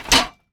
metal_hit_small_04.wav